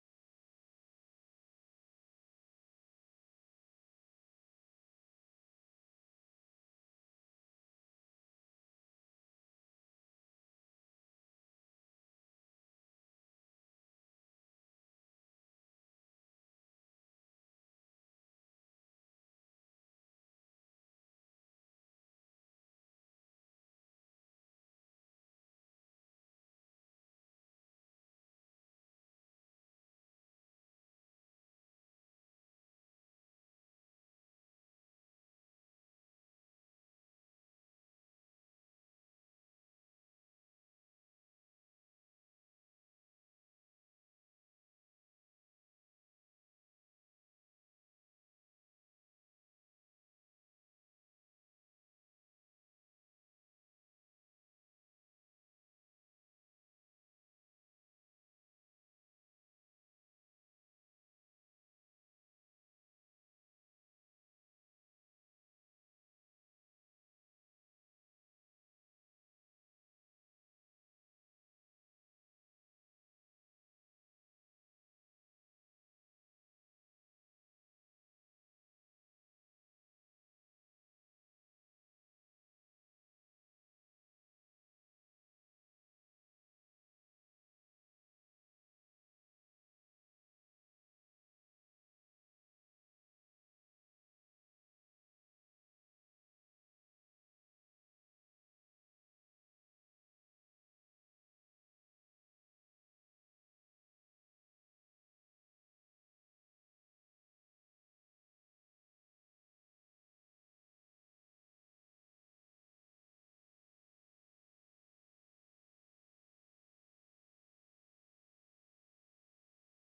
Аудиокнига Смешное в страшном | Библиотека аудиокниг